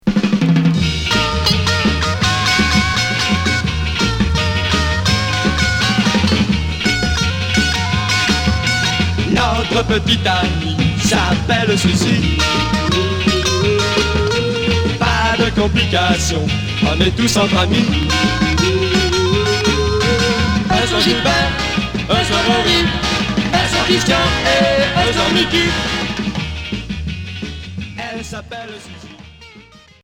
Beat rock